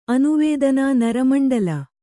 ♪ anuvēdanānaramaṇḍala